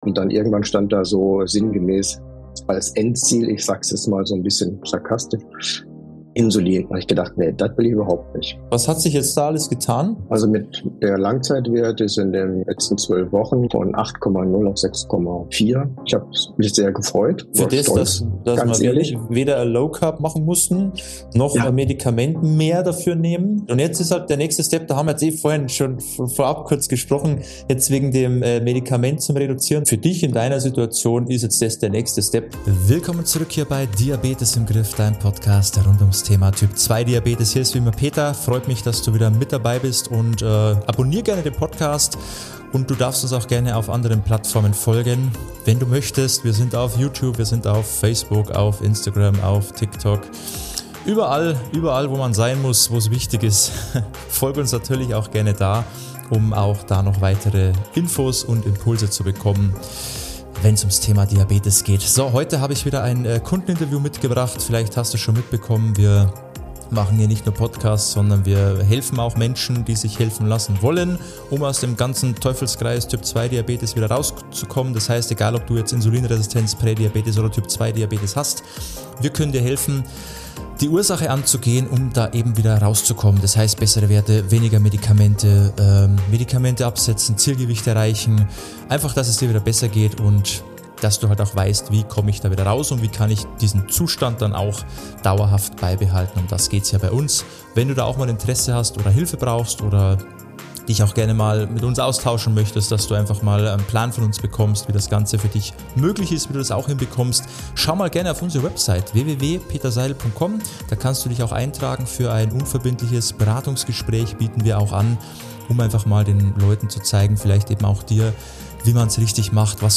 Ein inspirierendes Interview